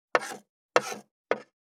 595肉切りナイフ,まな板の上,
効果音厨房/台所/レストラン/kitchen食器食材